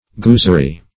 Search Result for " goosery" : The Collaborative International Dictionary of English v.0.48: Goosery \Goos"er*y\, n.; pl. Gooseries . 1.
goosery.mp3